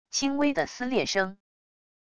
轻微的撕裂声wav音频